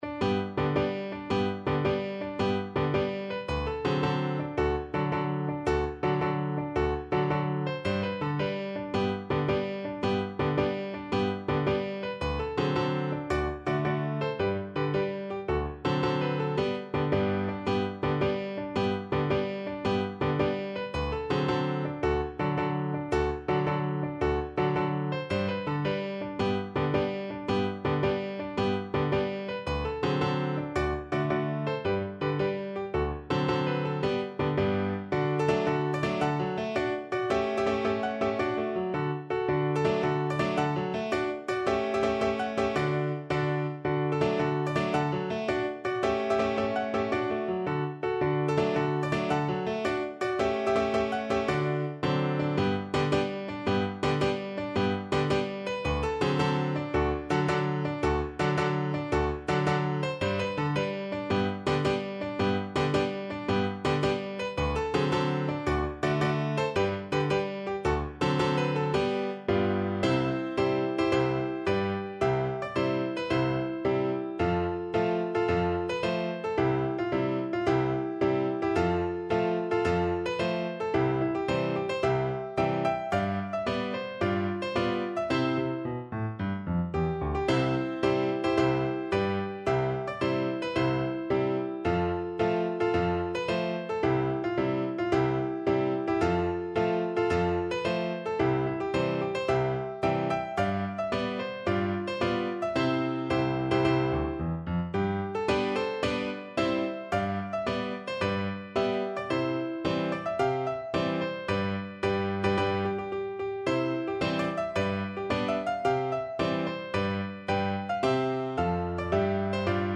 Classical Piefke, Johann Gottfried Königgrätzer March Piano version
No parts available for this pieces as it is for solo piano.
6/8 (View more 6/8 Music)
G major (Sounding Pitch) (View more G major Music for Piano )
Classical (View more Classical Piano Music)
piefke_koniggratzer_marsch_PNO.mp3